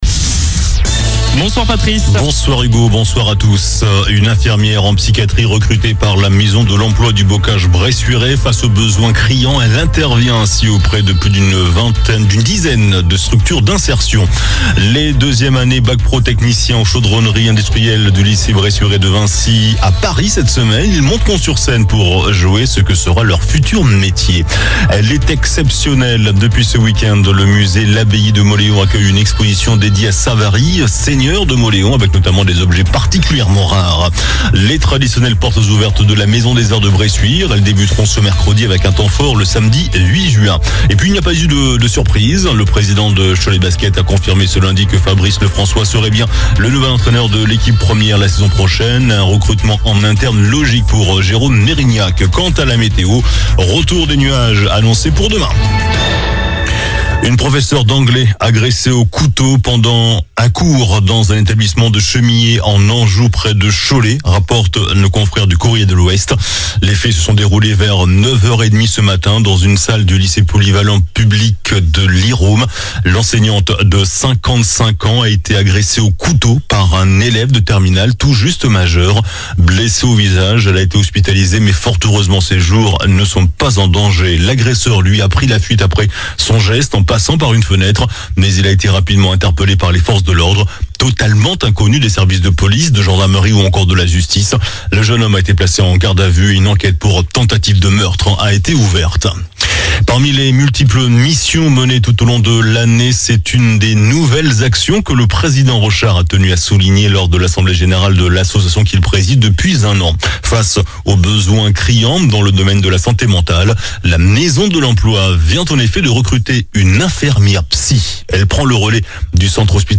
JOURNAL DU LUNDI 27 MAI ( SOIR )